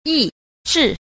1. 抑制 – yìzhì – ức chế (cấm đoán)
yi_zhi.mp3